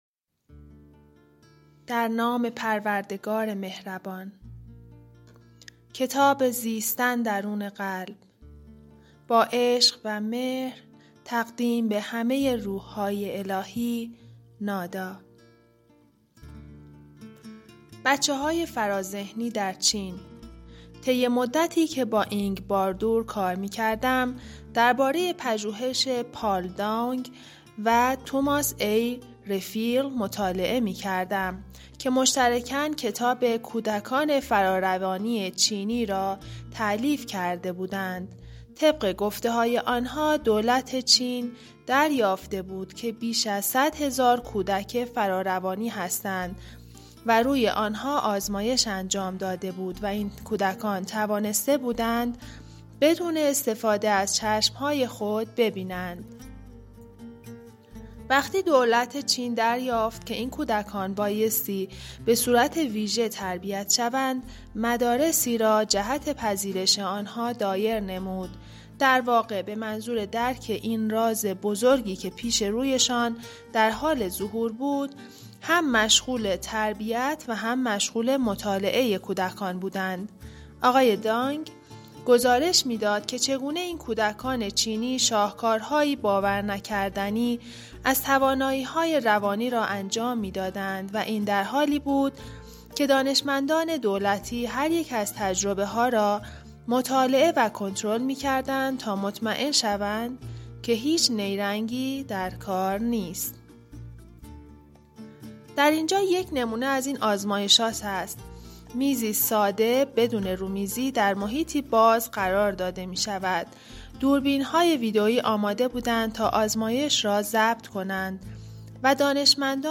کتاب گویای زیستن درون قلب نویسنده درونوالو ملچیزدک / قسمت5